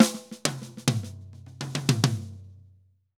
Drum_Break 100_1.wav